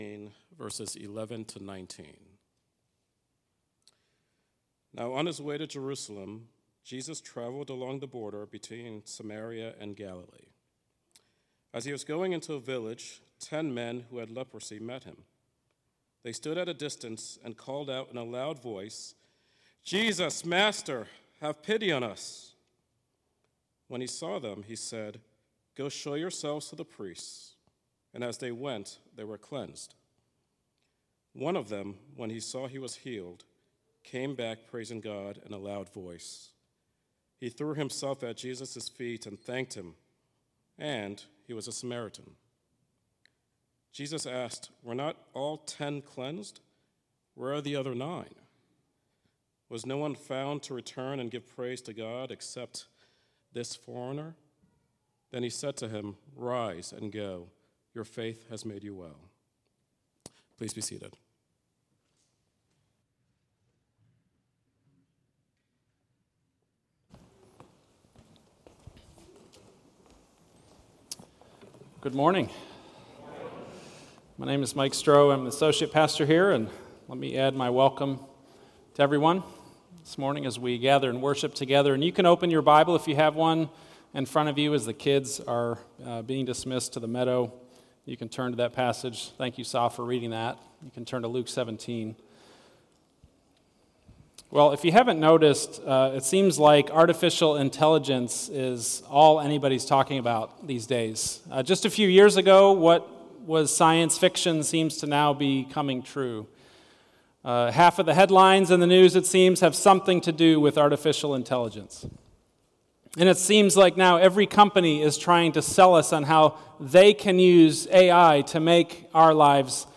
Hear expository sermons from the teaching team of Trinity Fellowship Church in Richardson, Texas.